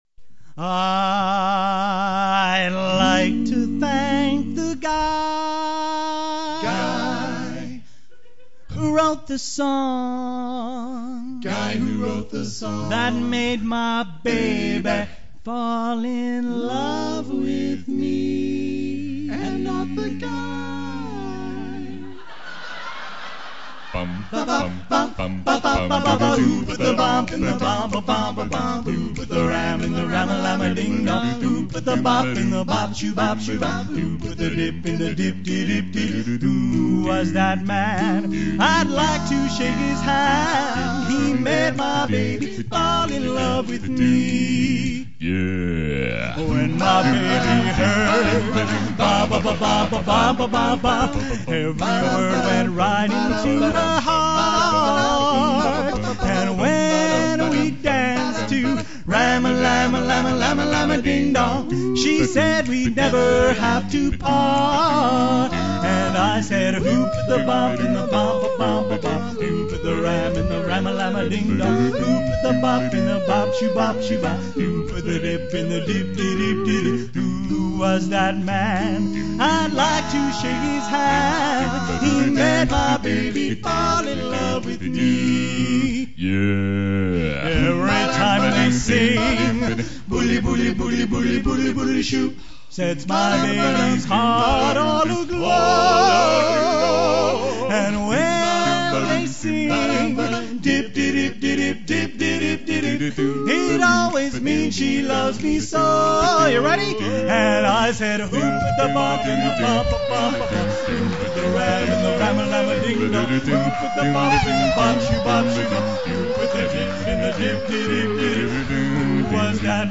November 11, 2000 at the Seattle Art Museum